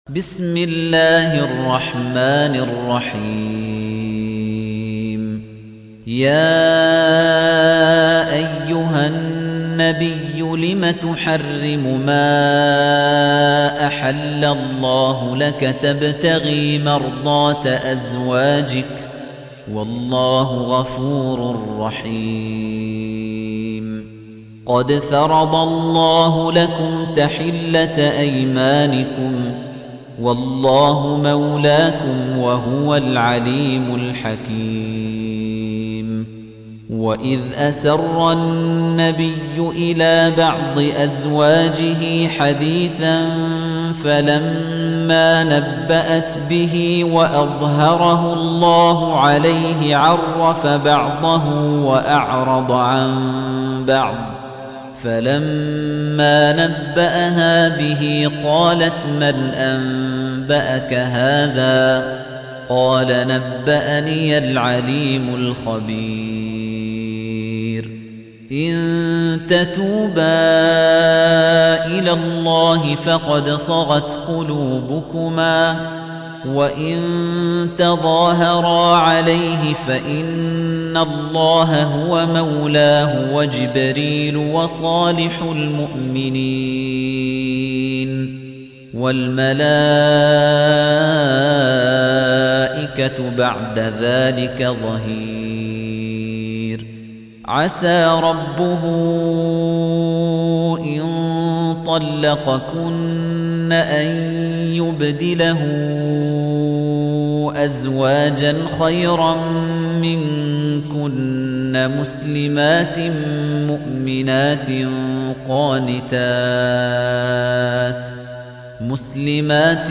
تحميل : 66. سورة التحريم / القارئ خليفة الطنيجي / القرآن الكريم / موقع يا حسين